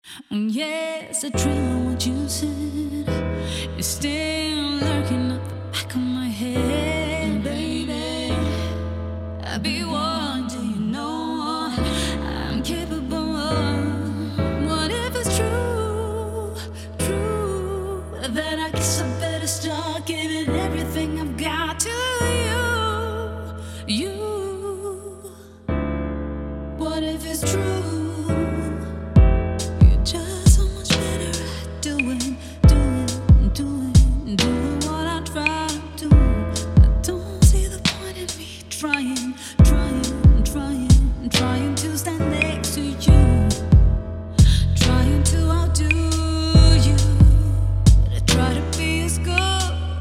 ozdobiona intrygującym wokalem